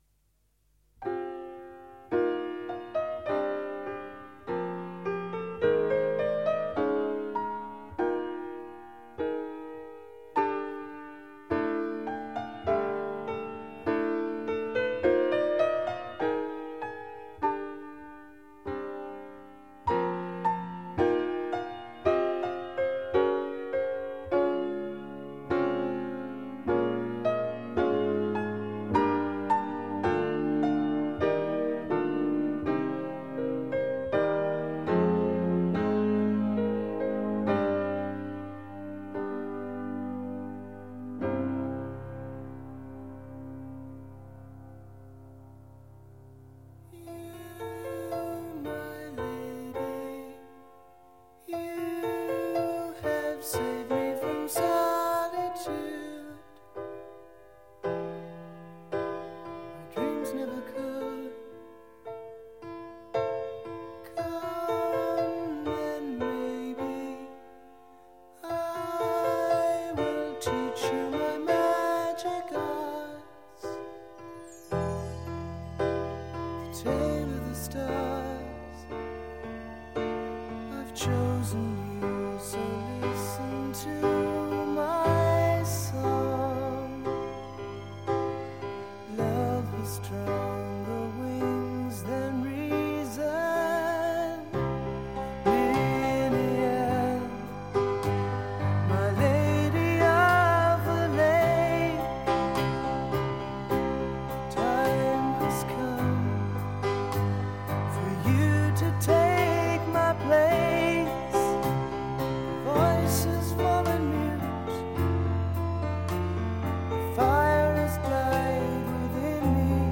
spotlights the classical influence
conservatory-trained keyboardist
A gorgeous ballad.